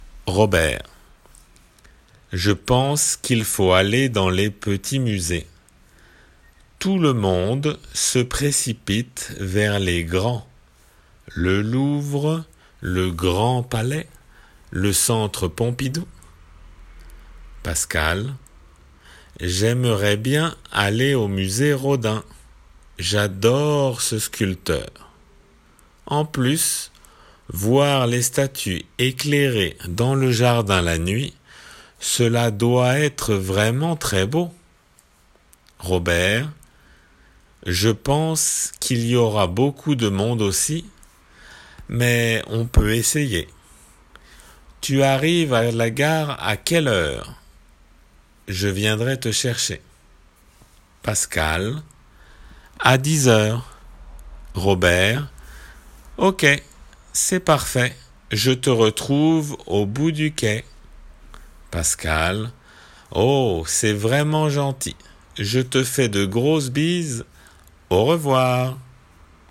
聞き取りの練習です。